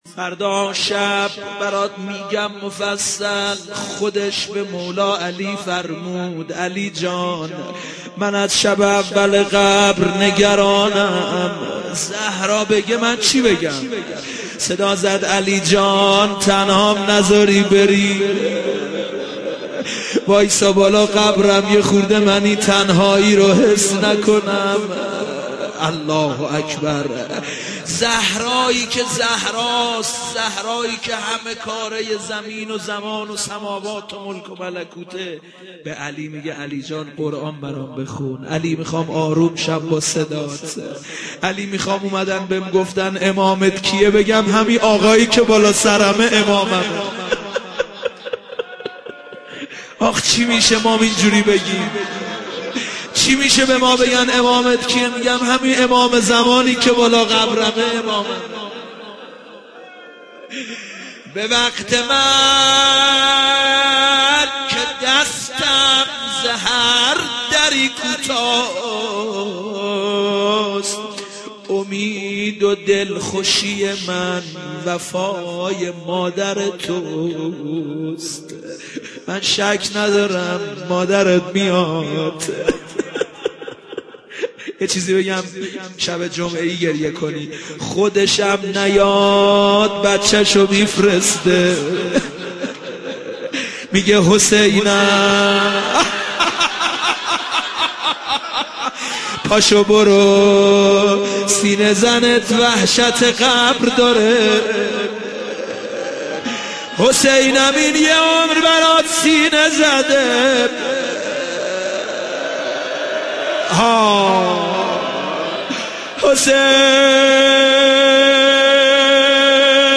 دانلود مداحی وحشت قبر - دانلود ریمیکس و آهنگ جدید